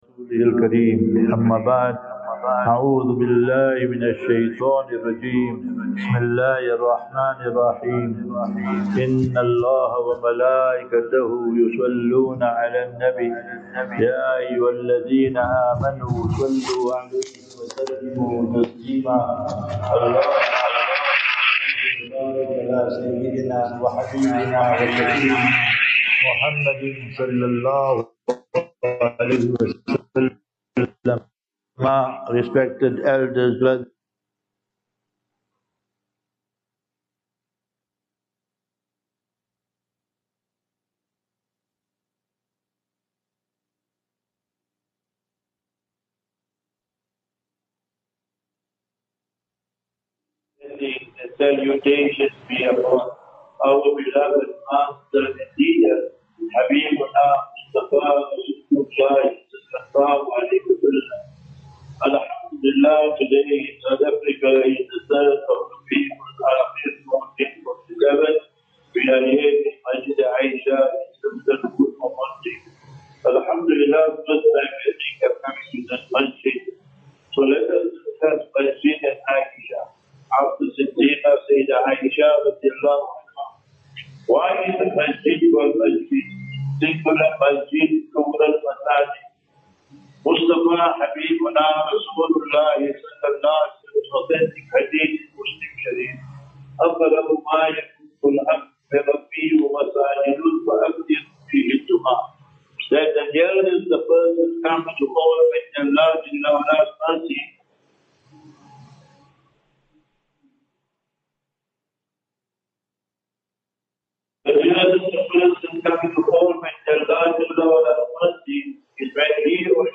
26 Sep 26 September 25 - Jumu'ah Lecture at Masjid A'isha (Ormonde)